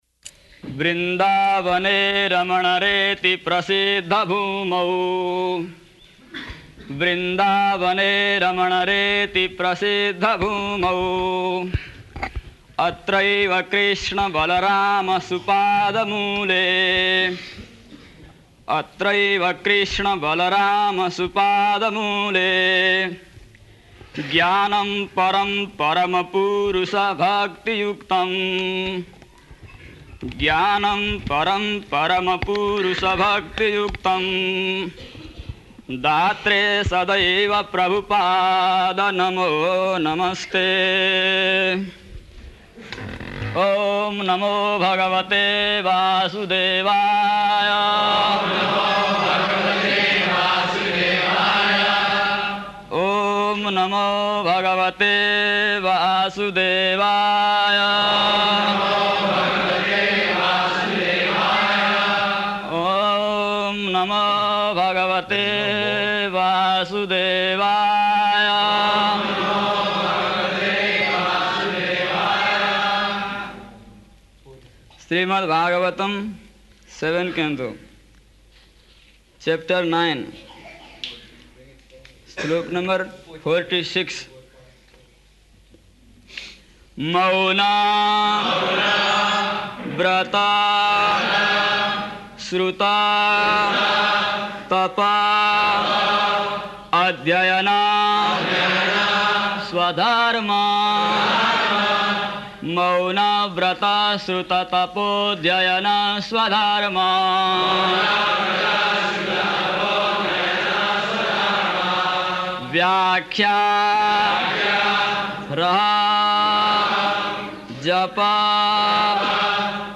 -- Type: Srimad-Bhagavatam Dated: April 1st 1976 Location: Vṛndāvana Audio file
[chants slokas] Oṁ namo bhagavate vāsudevāya.
[devotees repeat] Śrīmad-Bhāgavatam 7.9.46 .
[chants synonyms; devotees respond]